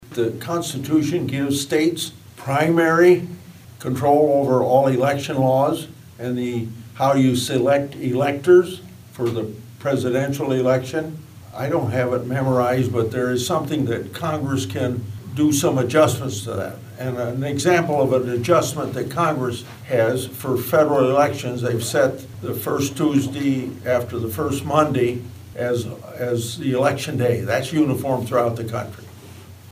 Grassley made his comments Tuesday during a stop in Audubon as part of his annual 99-county tour.